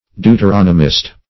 \Deu`ter*on"o*mist\